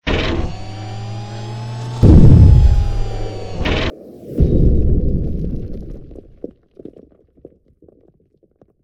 rocketgroundin.ogg